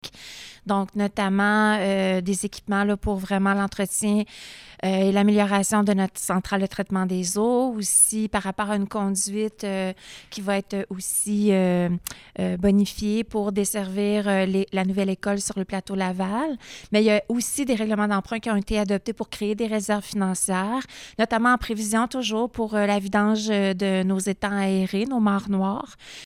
Il y en avait pour près de 9 M$ pour des aménagements utilitaires comme l’a expliqué la mairesse, Lucie Allard.